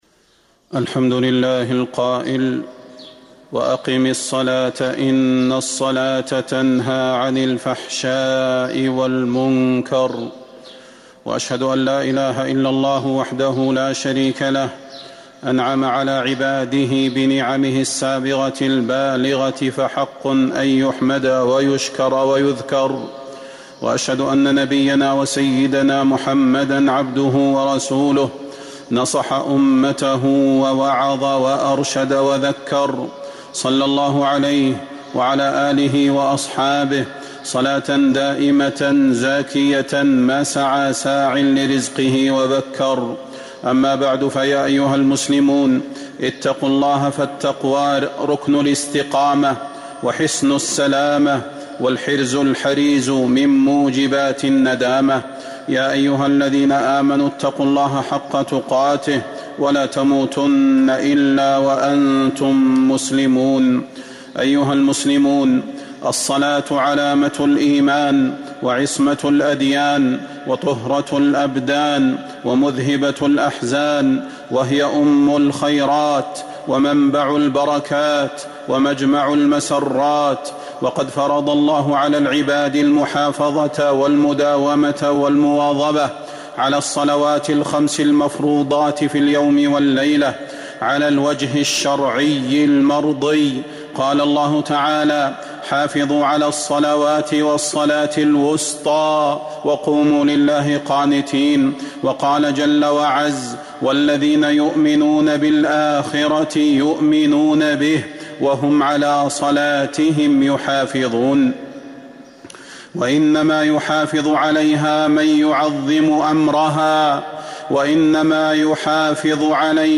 المدينة: موعظة الصلاة - صلاح بن محمد البدير (صوت - جودة عالية. التصنيف: خطب الجمعة